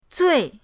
zui4.mp3